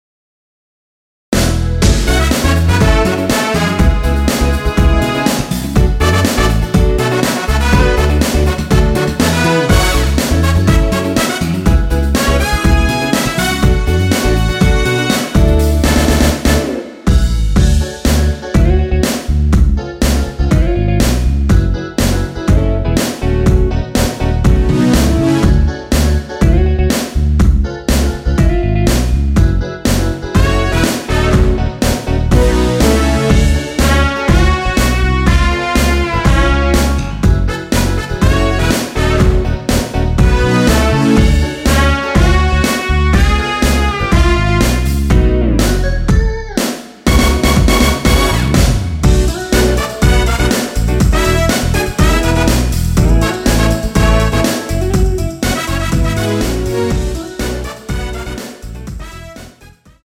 원키에서(-1)내린 MR입니다.
F#m
앞부분30초, 뒷부분30초씩 편집해서 올려 드리고 있습니다.